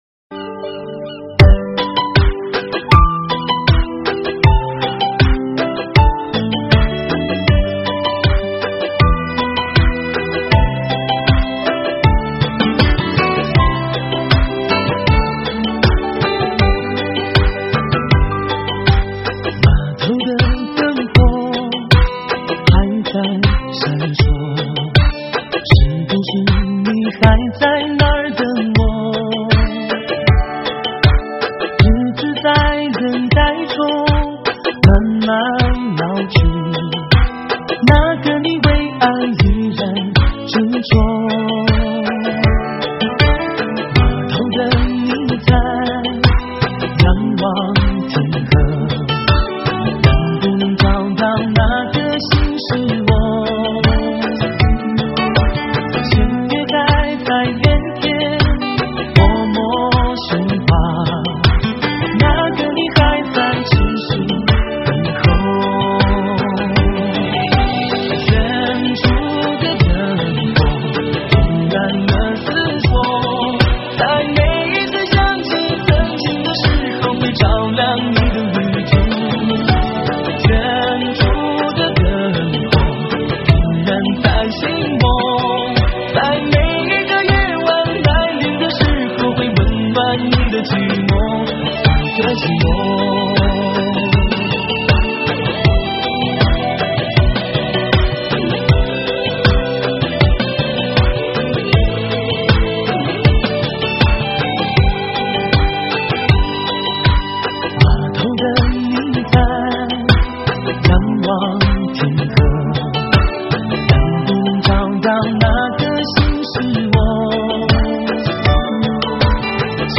采用Disco这样快节奏的音乐。